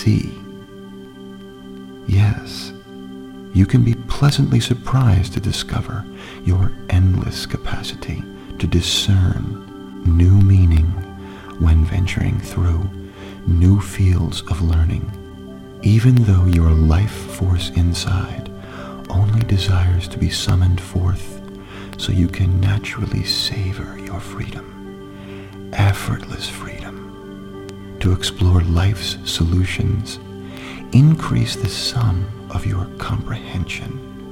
The Amazing Learning Hypnosis session will plant suggestions into your controlling subconscious mind which will help improve your focus so you never switch off while studying.